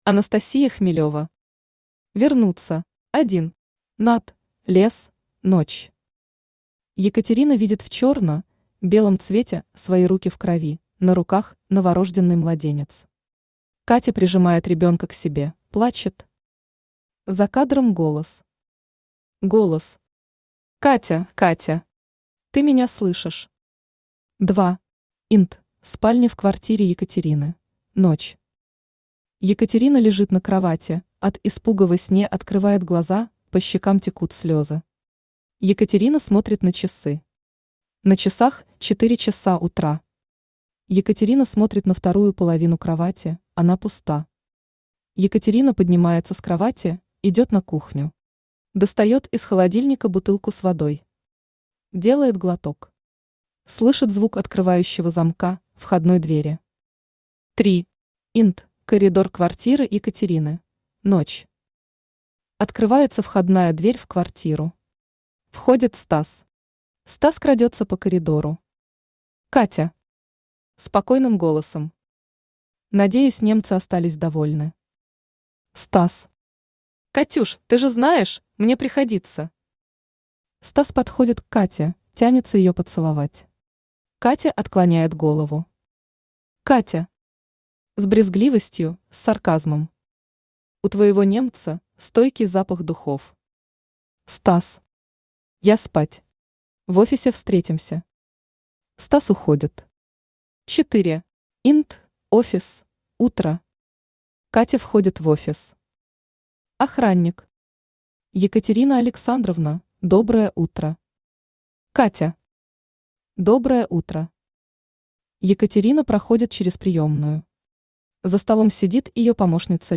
Аудиокнига Вернуться | Библиотека аудиокниг
Aудиокнига Вернуться Автор Анастасия Хмелева Читает аудиокнигу Авточтец ЛитРес.